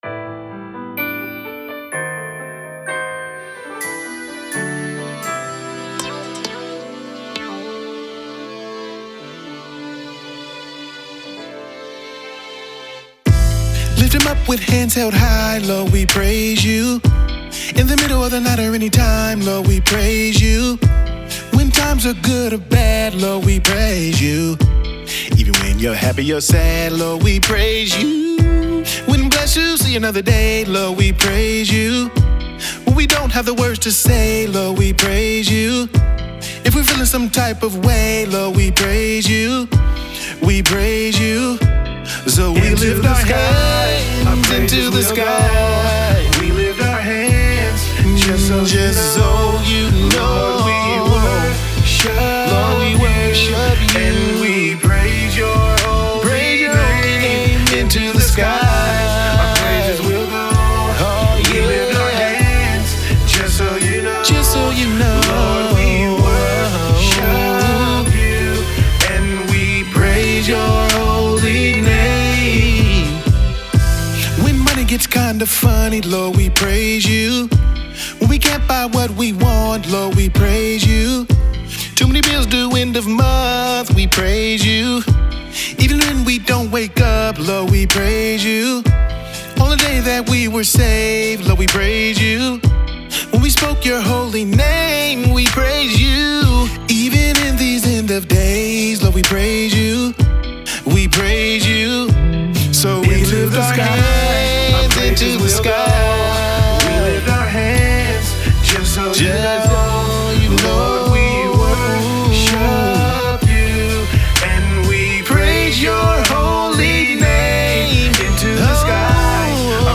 R&G is a smooth blend of R&B, Gospel, Hip Hop and Pop.